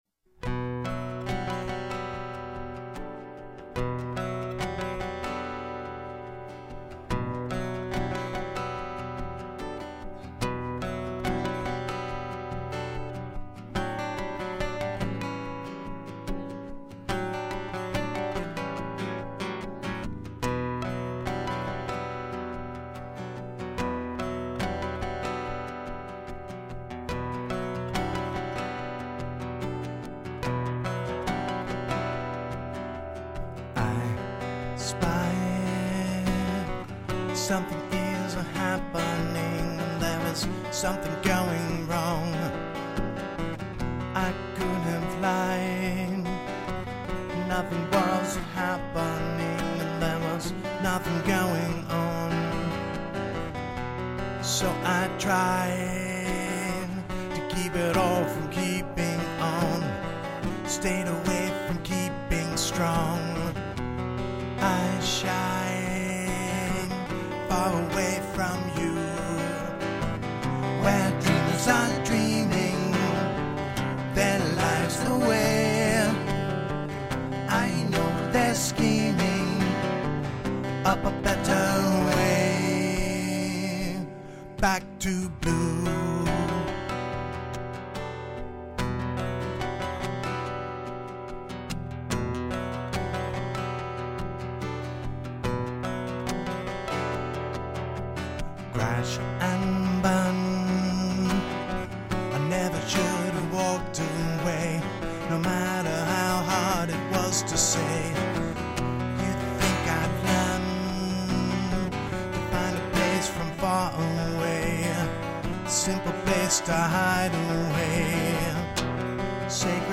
All Original Indy Rock Sound